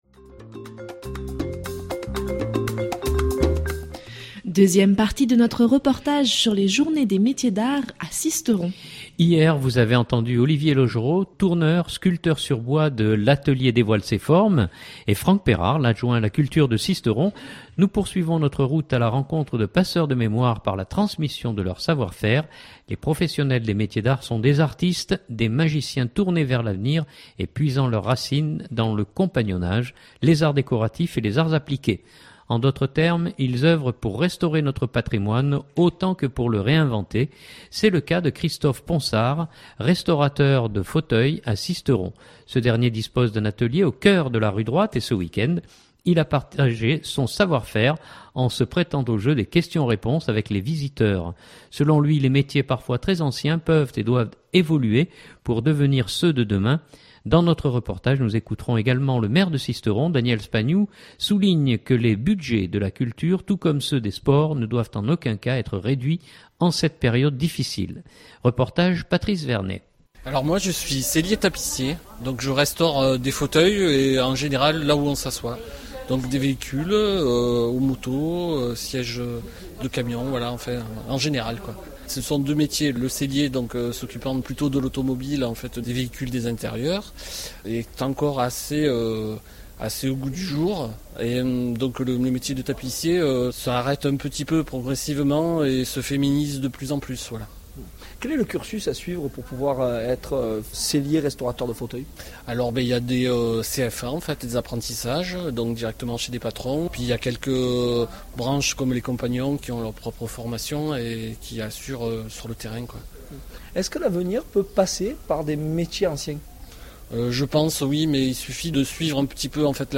DEUXIÈME PARTIE DE NOTRE REPORTAGE SUR LES JOURNÉES DES MÉTIERS D’ART A SISTERON !
Selon lui, les métiers parfois très anciens, peuvent et doivent évoluer pour devenir ceux de demain. Dans notre reportage, nous écouterons également le Maire de Sisteron, Daniel Spagnou souligne que les budgets de la culture, tout comme ceux des sports, ne doivent en aucun cas être réduits en cette période difficile.